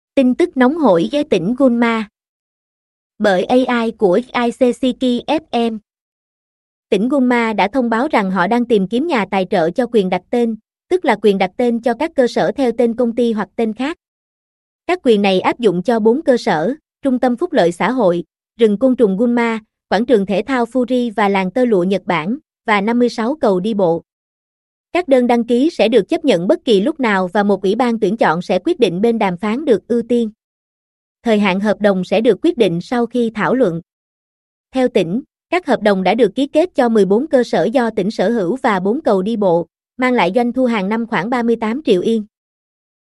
Tin tức nóng hổi "Tỉnh Gunma".Bởi AI của "Isesaki FM".Tỉnh Gunma đã thông báo rằng họ đang tìm kiếm nhà tài trợ cho quyền đặt tên, tức là quyền đặt tên cho các cơ sở theo tên công ty hoặc tên khác.
Audio Channels: 1 (mono)